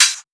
Techno Open Hat 02.wav